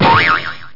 1 channel
BOING.mp3